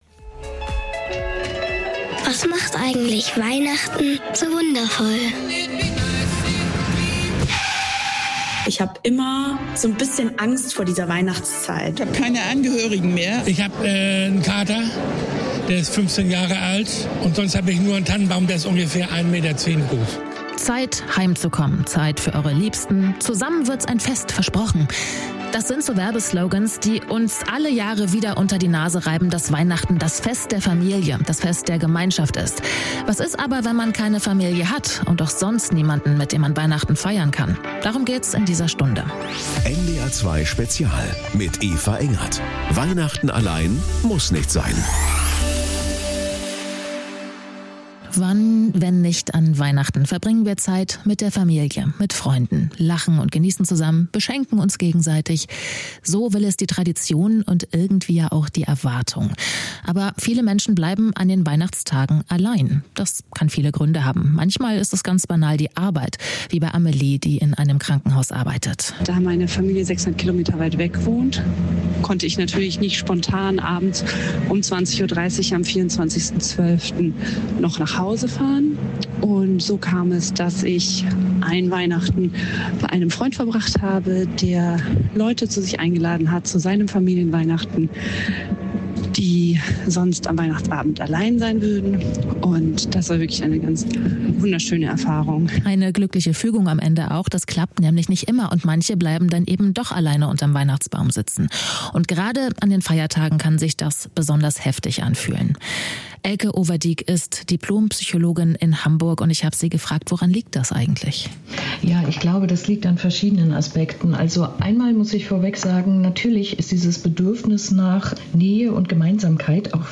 Regelmäßig besprechen wir donnerstags nach den NDR 2 Nachrichten um 18 Uhr ein aktuelles Thema. Ob politischer Machtwechsel, Orkan oder Lebensmittelskandal - im "NDR 2 Spezial" geben wir einen umfassenden Überblick und sprechen mit Experten, Korrespondenten und Betroffenen.